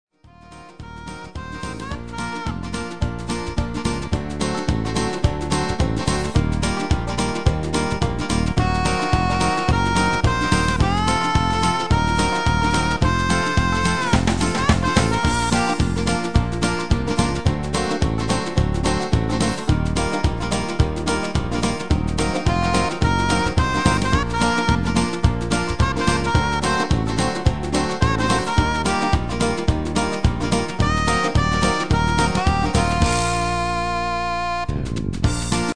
Midifile gearrangeerd in de stijl van:
Demo/Koop midifile
Genre: Country & Western
- Vocal harmony tracks
Demo's zijn eigen opnames van onze digitale arrangementen.